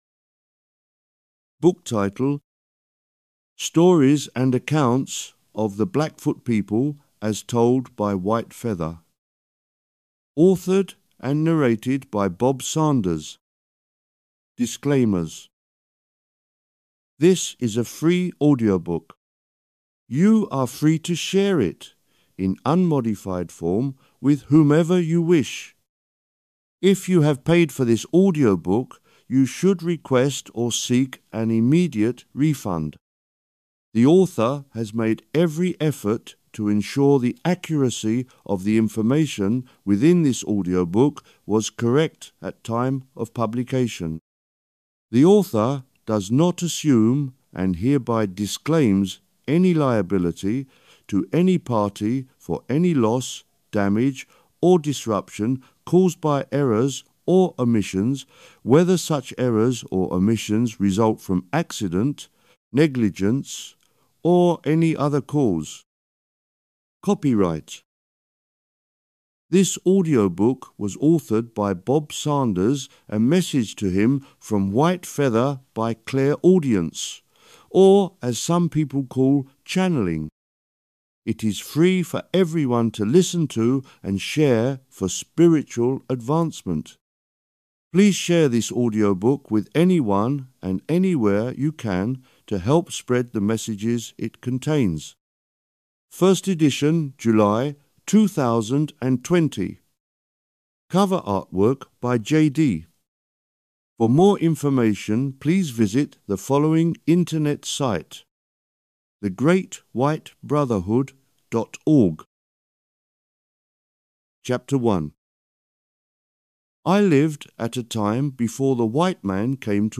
Stories And Accounts Of The Blackfoot People - Audiobook.mp3